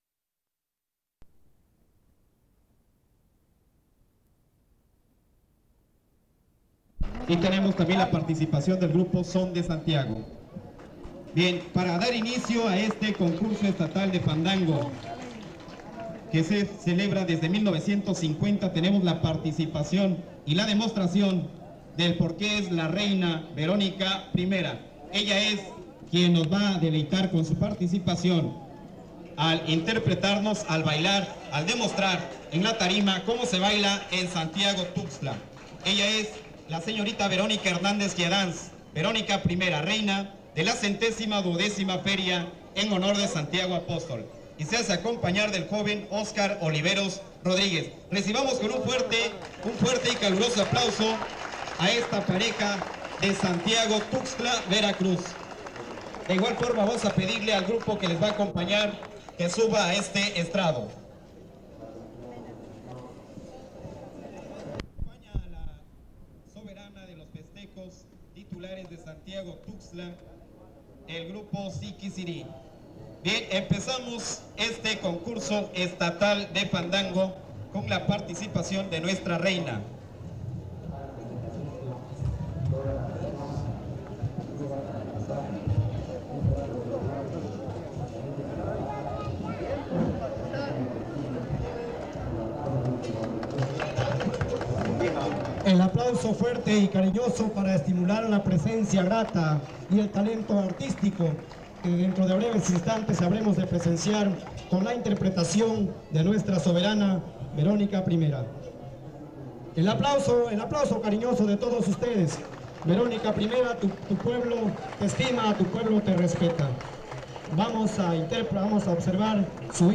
Archivo | 01 Concurso Estatal de Fandango | ID: 6395w9991 | Colecciones Digitales de El Colegio de México